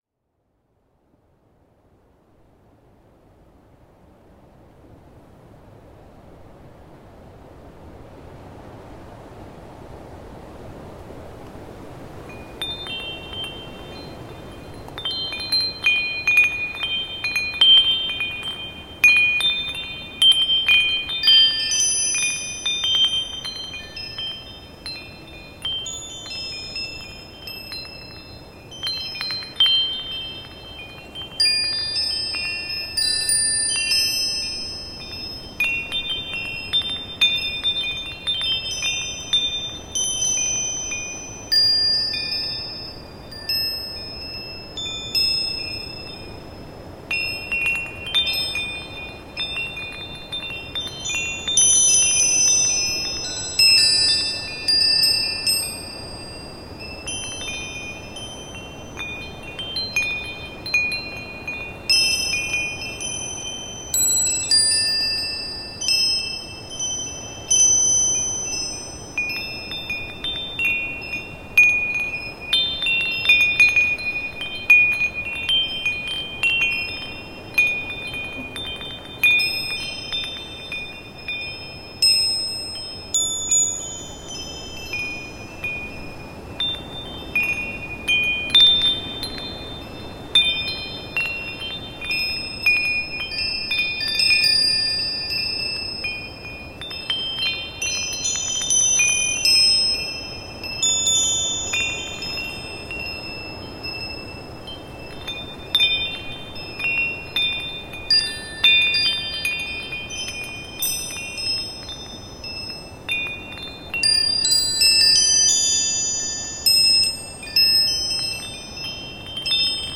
nature sounds and atmospheric sounds, perfect for relaxation
new age
ambient